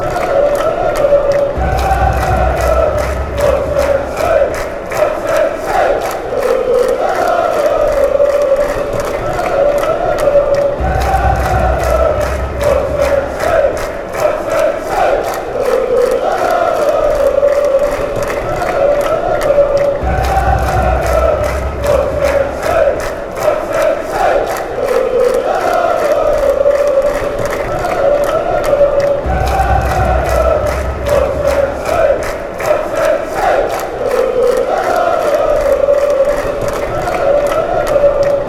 football songs
soccer chants